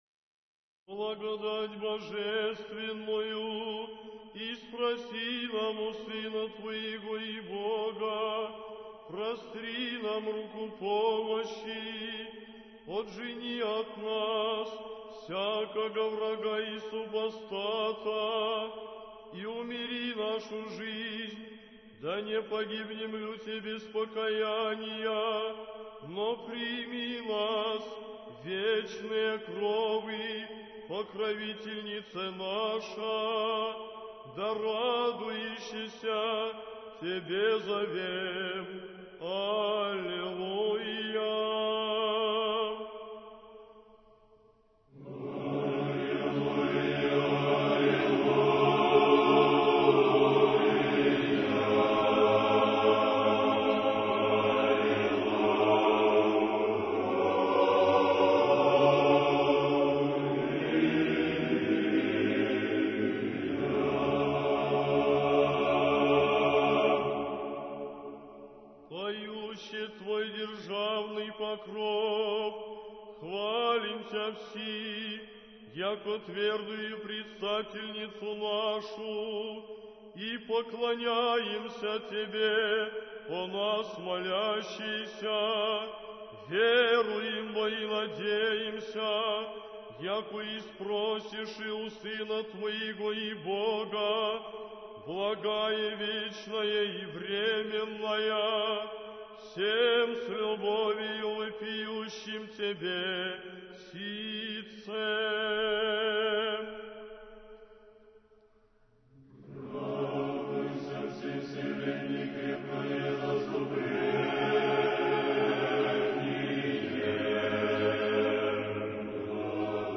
Духовная музыка